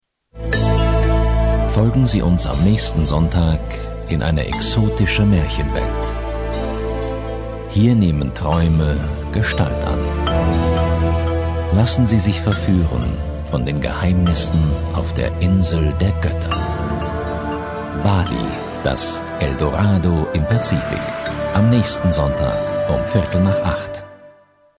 Prägnante tiefe Stimme mit großer Variationsbreite
Sprechprobe: Industrie (Muttersprache):
Great deep German voice